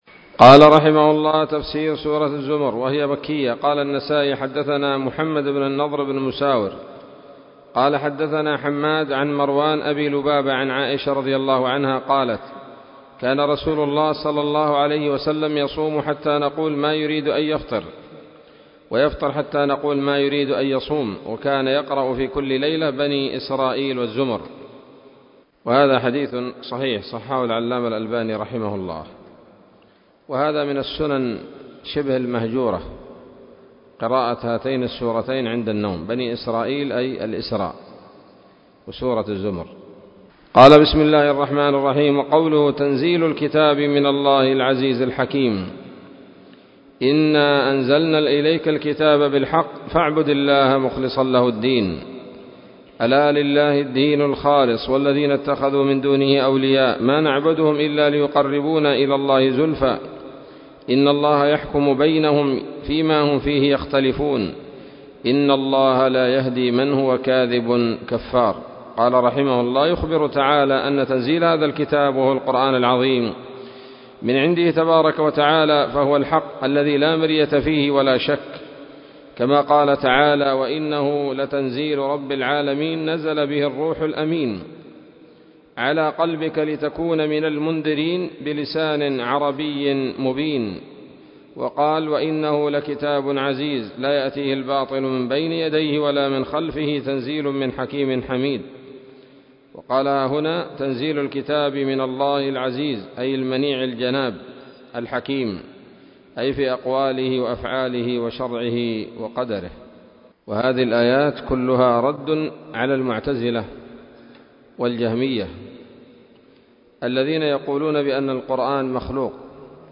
039 سورة الزمر الدروس العلمية تفسير ابن كثير دروس التفسير